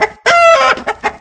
Chicken.ogg